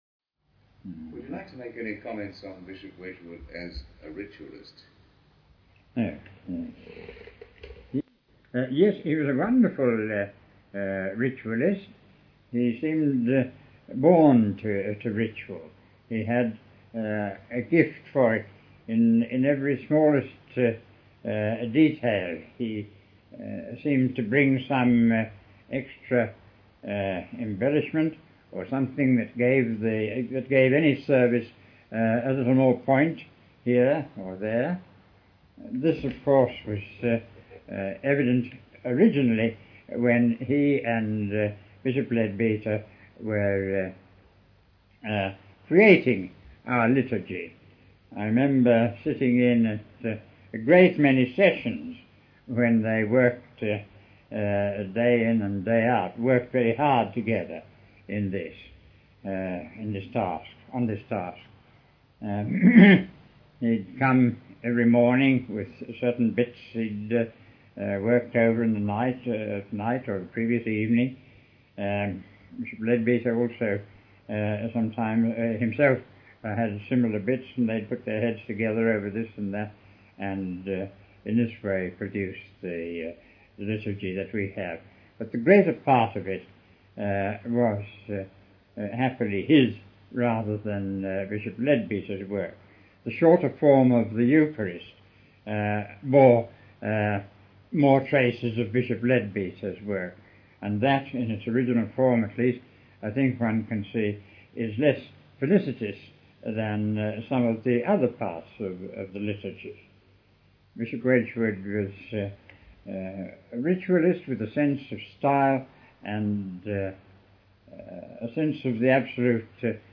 Audio recording of Bishop Leadbeater speaking on �To Those Who Mourn�, about the certainty of life after death,1925.